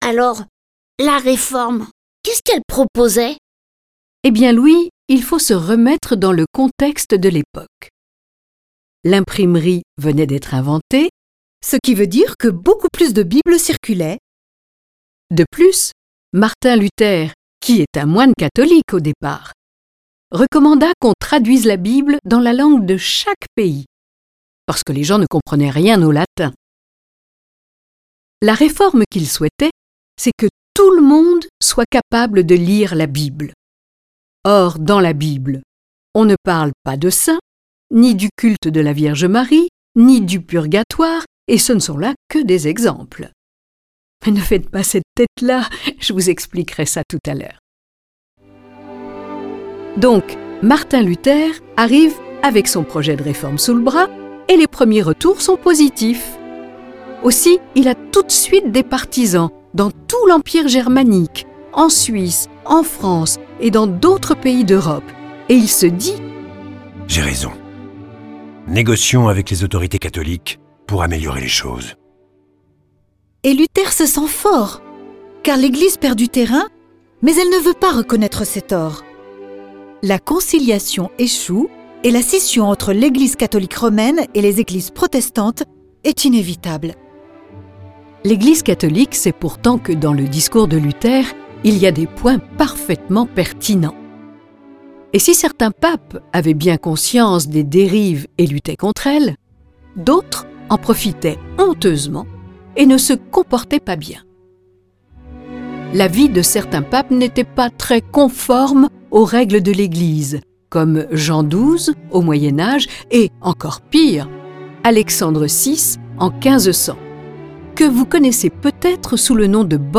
Diffusion distribution ebook et livre audio - Catalogue livres numériques
Ce récit est animé par 6 voix et accompagné de plus de 30 morceaux de musique classique.